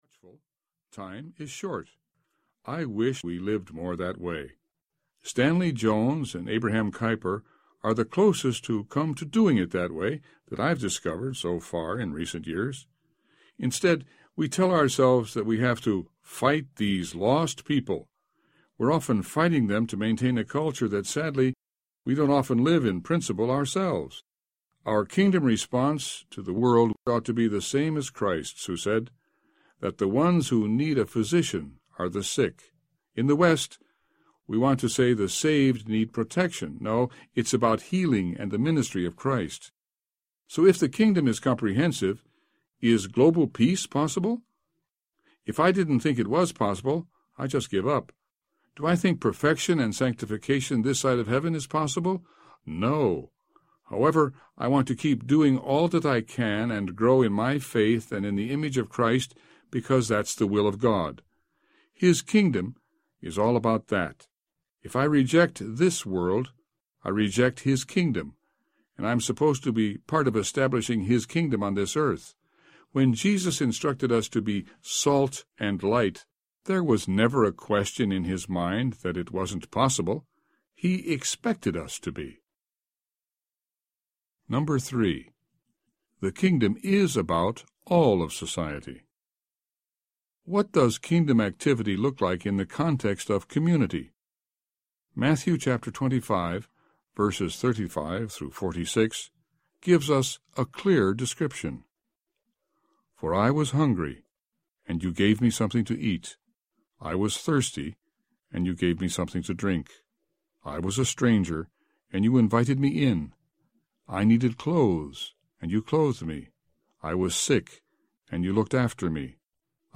Glocalization Audiobook
Narrator
7.7 Hrs. – Unabridged